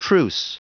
Prononciation du mot truce en anglais (fichier audio)
Prononciation du mot : truce